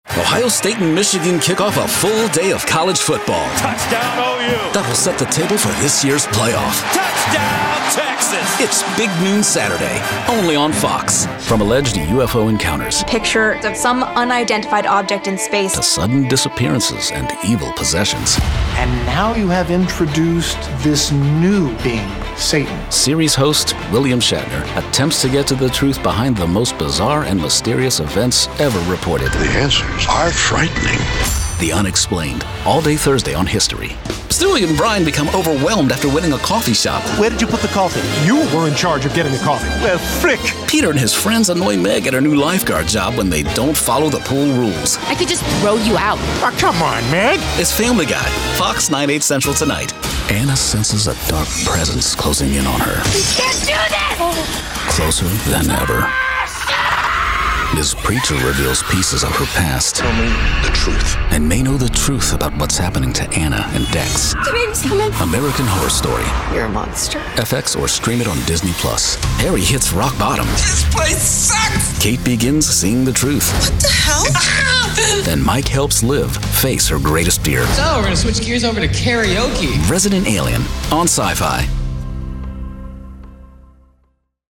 Narrator with a strong, confident and friendly voice to tell your story.